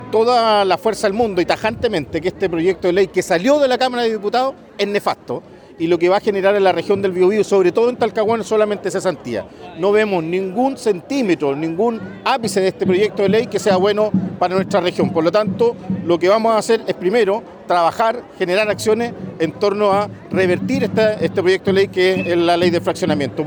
El alcalde de Talcahuano, Eduardo Saavedra, anunció que la próxima semana junto al gobernador Sergio Giacaman, expondrán en la Comisión de Pesca para dar cuenta del problema. Tildó el proyecto de “nefasto” y aseguró que solo generará cesantía.